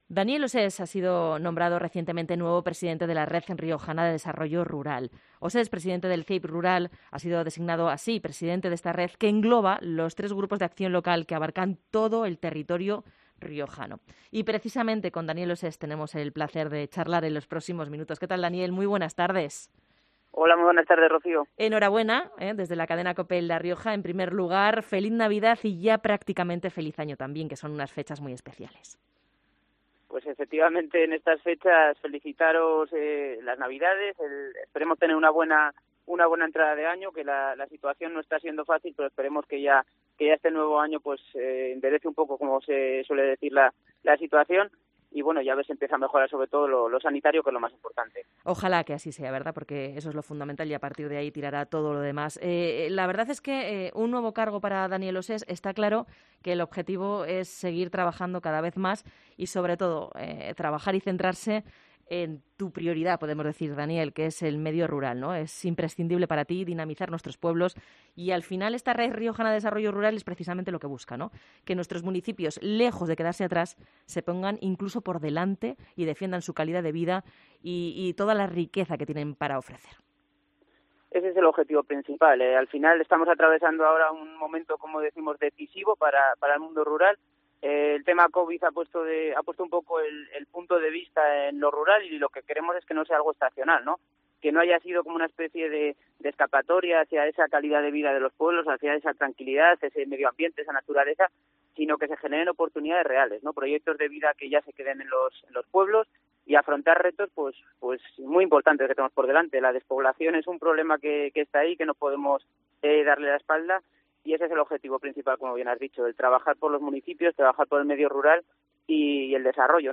Ha pasado por los micrófonos de COPE Rioja para explicarnos cuáles son sus retos al frente de esta Red y cuál es la situación que atraviesan los municipios riojanos.